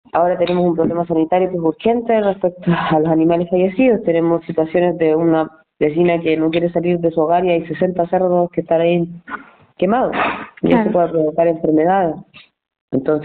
alcaldesa-animales-muertos.mp3